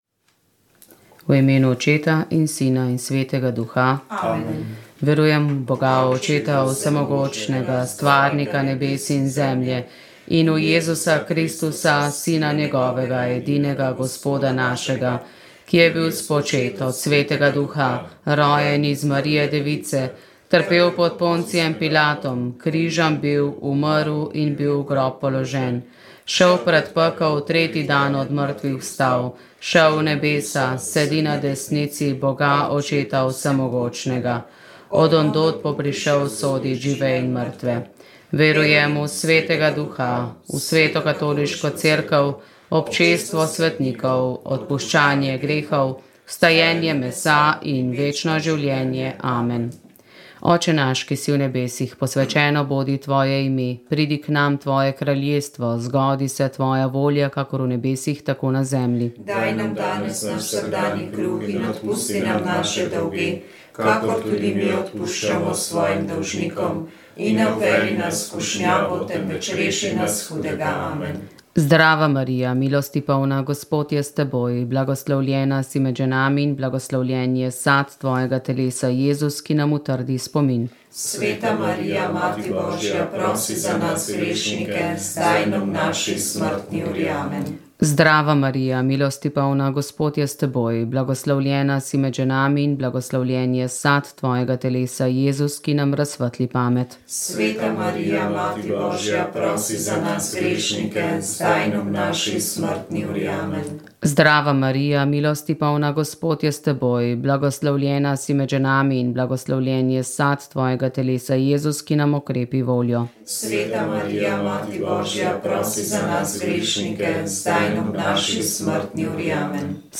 Rožni venec
Molile so redovnice - usmiljenke.